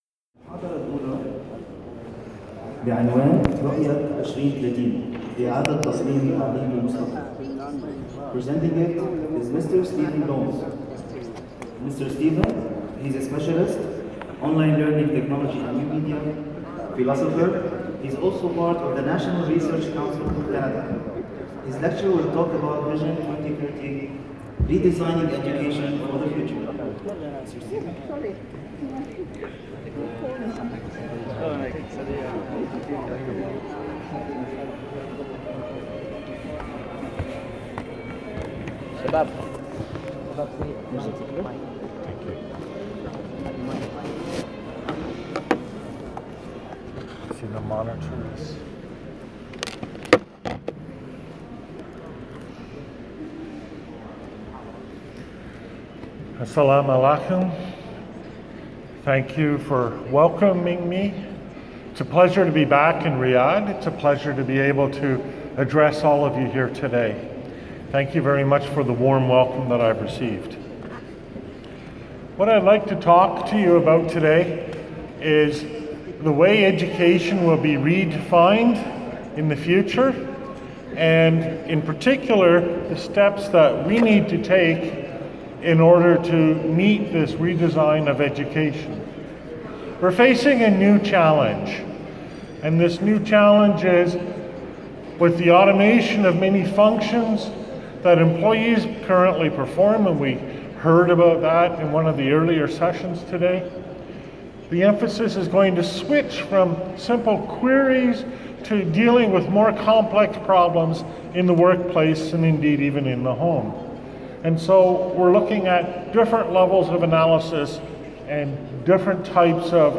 Vision 2030: Redesigning Education for the Future Previous Next Page: / Author: Downloads: (Old style) [ Slides ] [ PDF ] [ Audio ] [ Video ] [] Asbar World Forum 2018, Asbar, Riyadh, Saudia Arabia, Keynote, Nov 06, 2018. In this presentation I outline three ways education needs to adapt to the future, by becoming more relevant, engaging and personal, and then describe the technologies and approaches we will need to develop and use in order to support this.